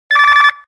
phonering.ogg